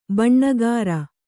♪ baṇṇagāra